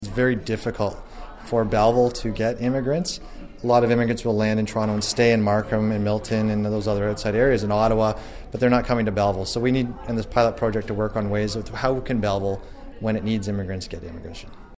Chair of the the Economic and Destination Development Committee Councillor Ryan Williams tells Quinte News the city is not just looking for immigrants, but looking for skilled workers.